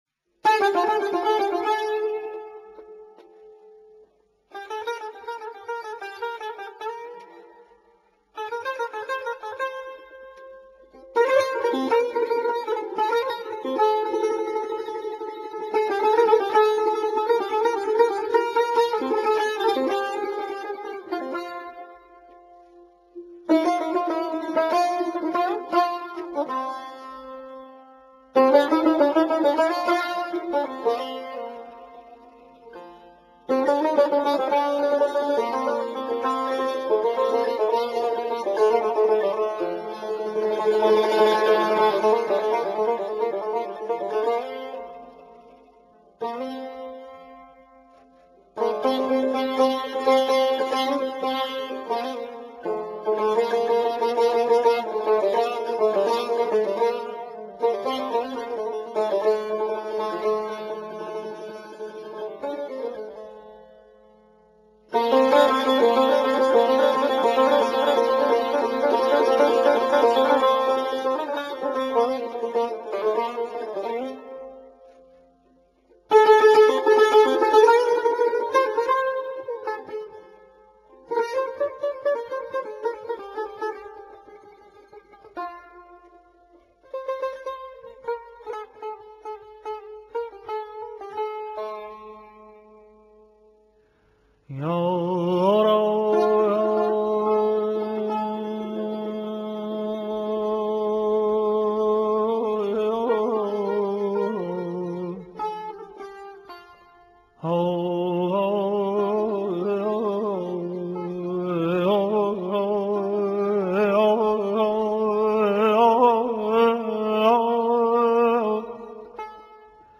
سنتی ایرانی صدا